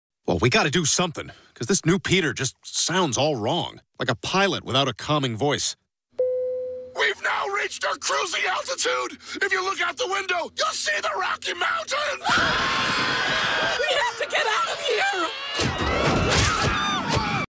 Pilot without a calming voice